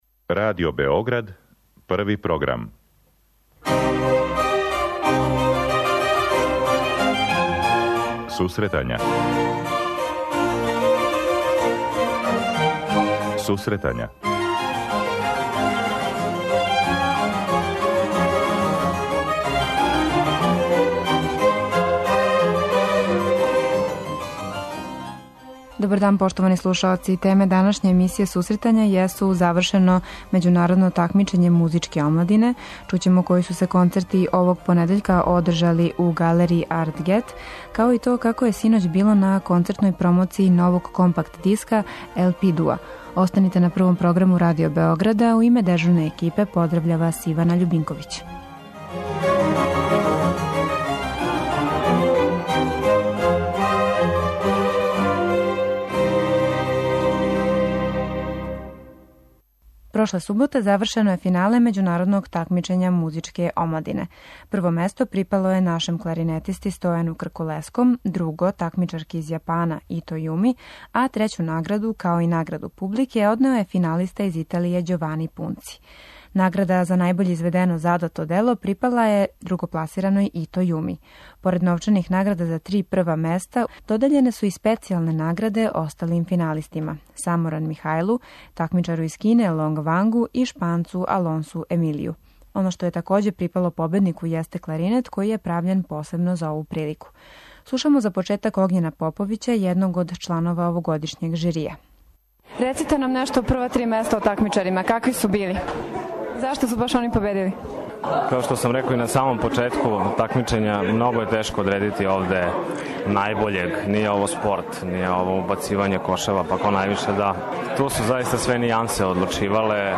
Преносимо вам и део атмосфере са синоћ одржаног концерта у УК Пароброд, када је наступио ЛП дуо.